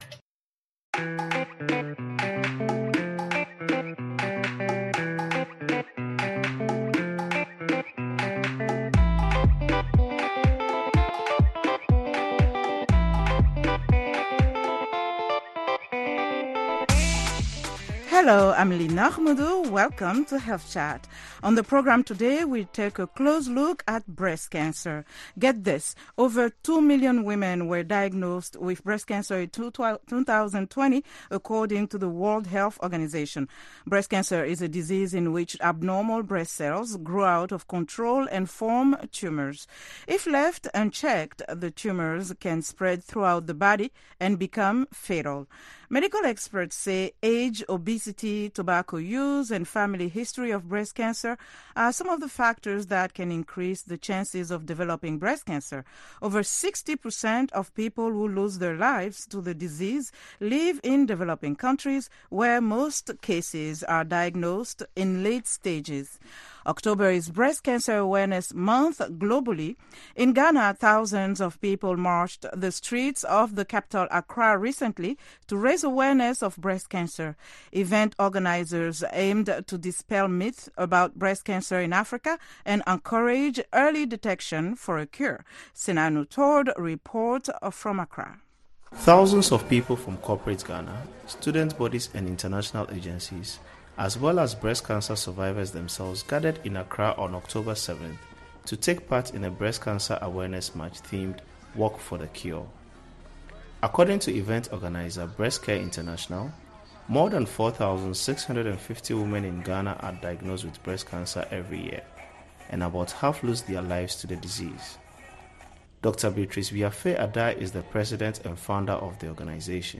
Health Chat is a live call-in program that addresses health issues of interest to Africa. The show puts listeners directly in touch with guest medical professionals. It includes a weekly feature spot, news and comments from listeners.